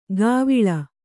♪ gāviḷa